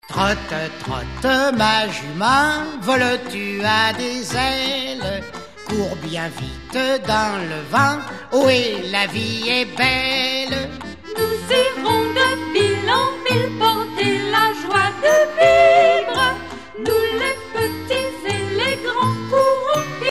Voix féminine